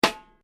Snare.mp3